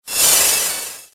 دانلود آهنگ باد 65 از افکت صوتی طبیعت و محیط
دانلود صدای باد 65 از ساعد نیوز با لینک مستقیم و کیفیت بالا
جلوه های صوتی